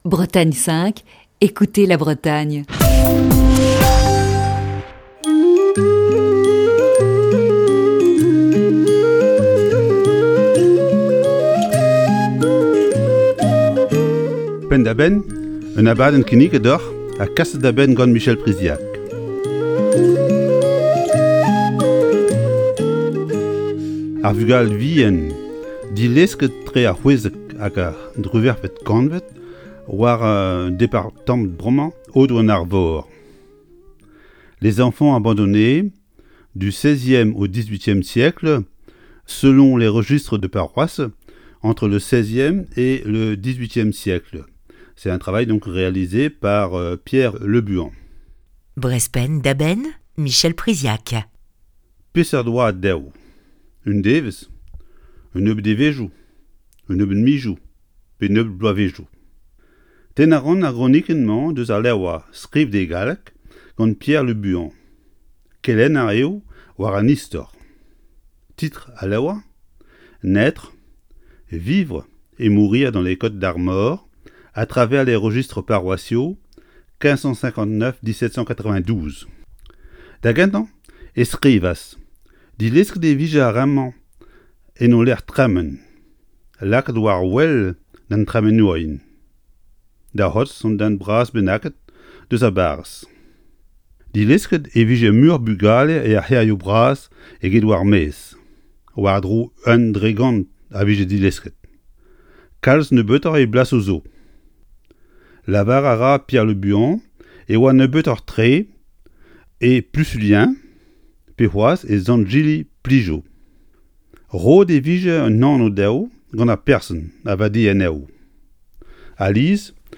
Chronique du 27 avril 2020.